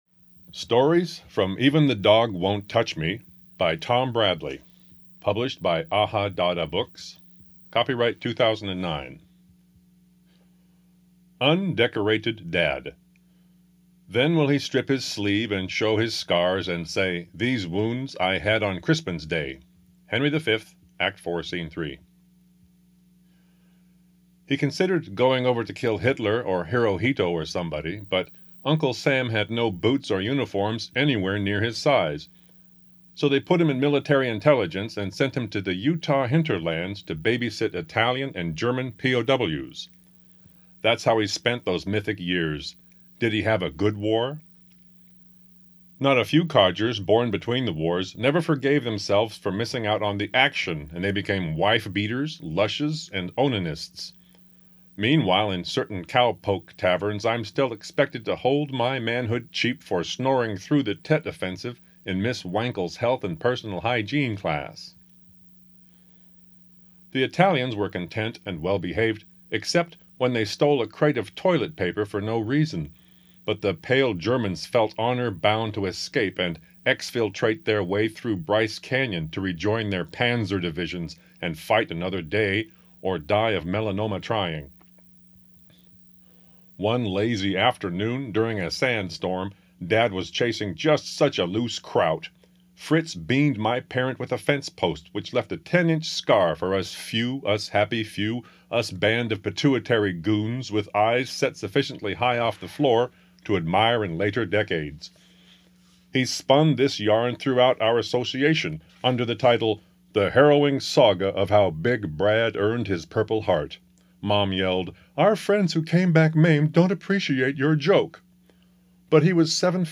Audio Book from Ahadada Press